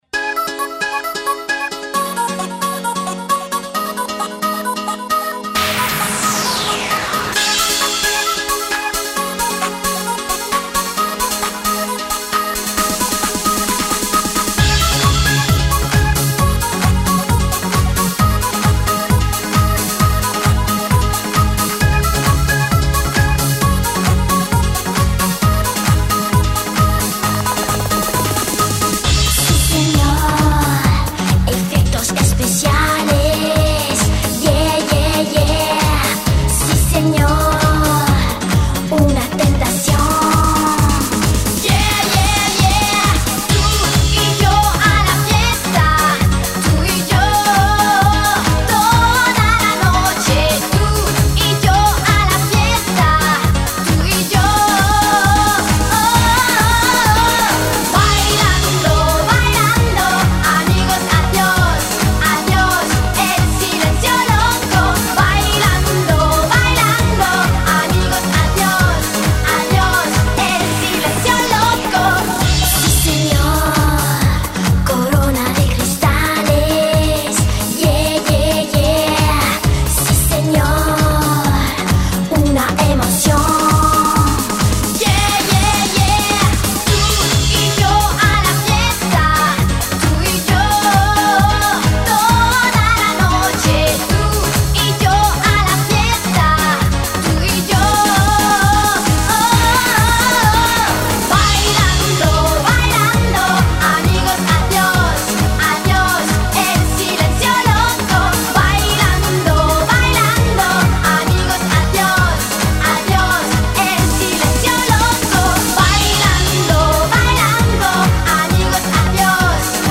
Назад в (pop)...